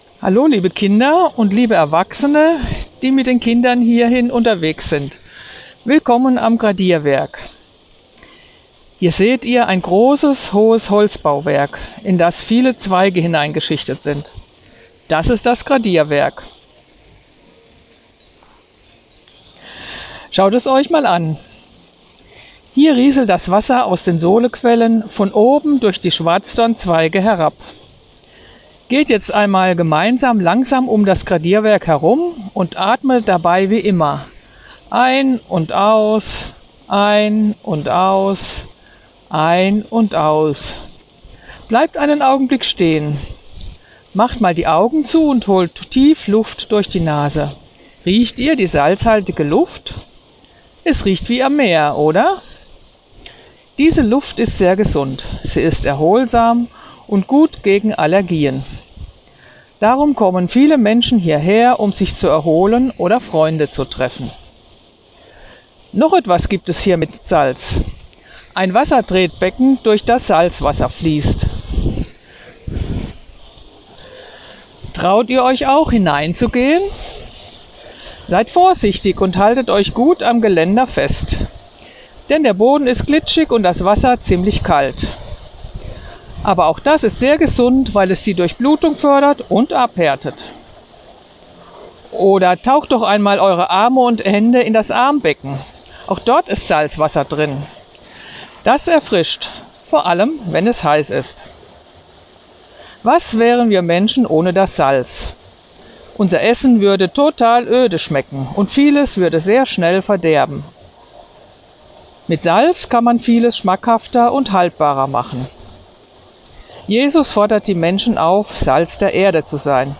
Salz-Atem-Meditation-am-Gradierwerk-fuer-Kinder-mp3.mp3